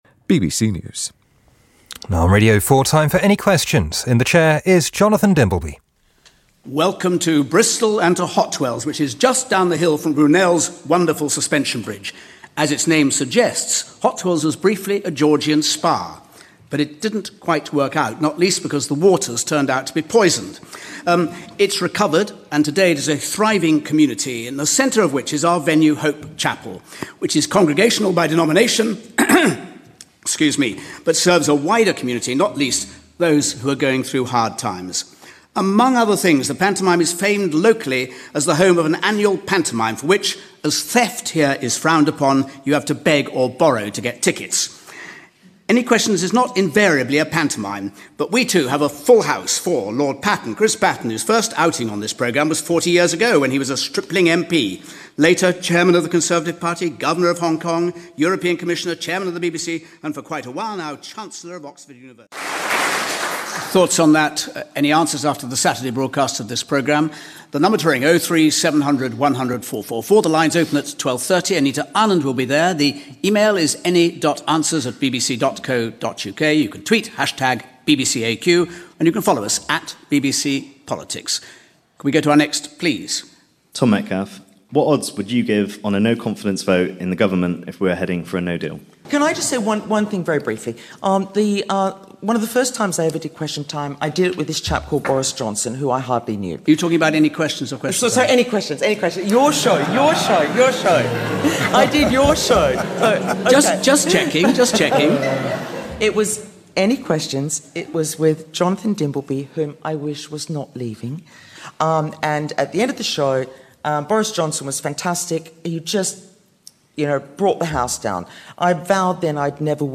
The final edition of the BBC's political debate programme, Any Questions, under Jonathan Dimbleby's chairmanship.